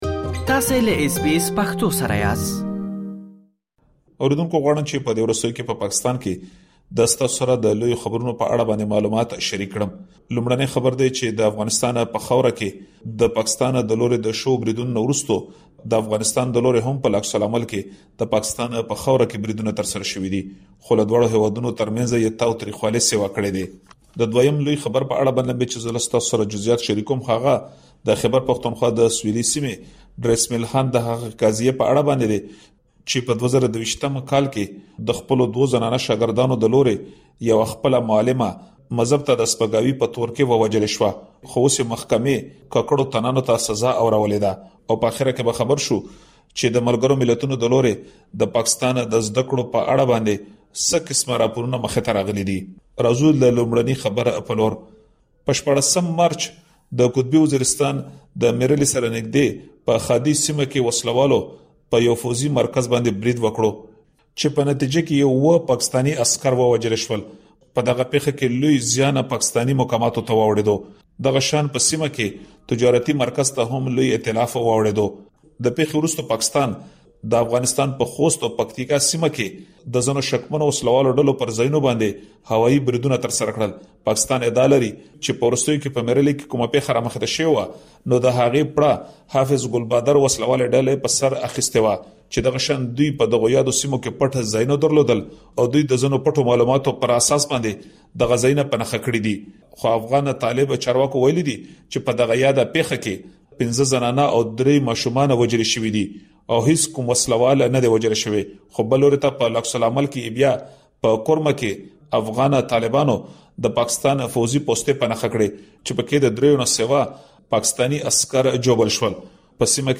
رپوټ دلته معلومات اورېدلی شئ